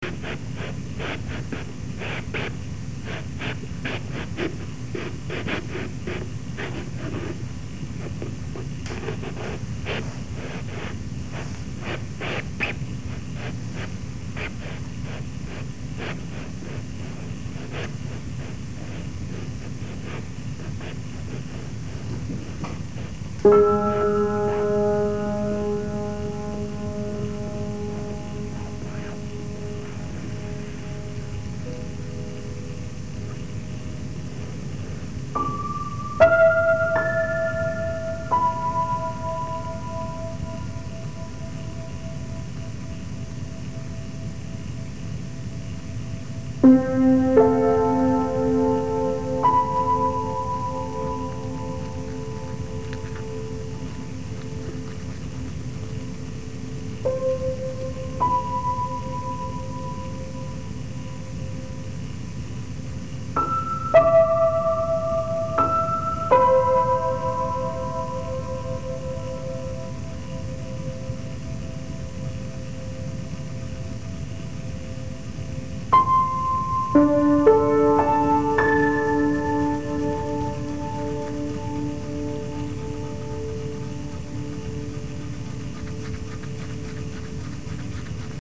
piano, guitar and small objects